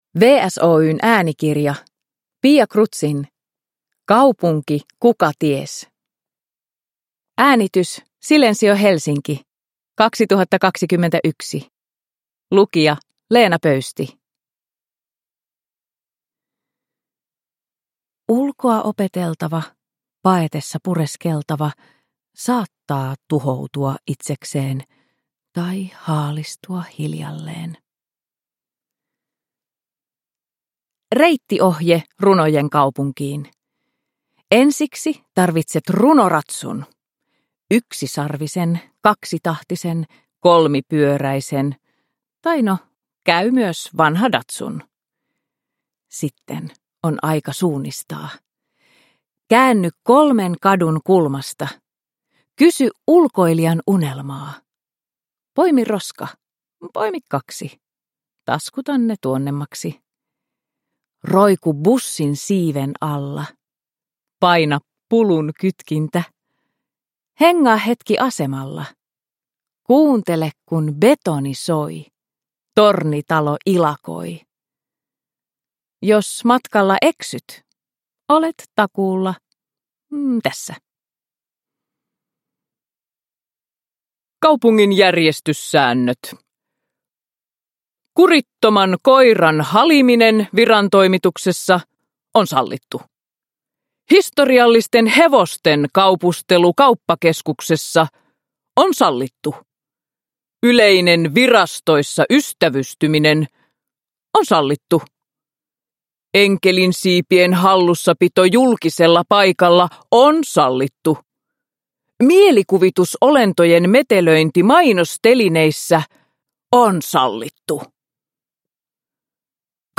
Kaupunki Kukaties – Ljudbok – Laddas ner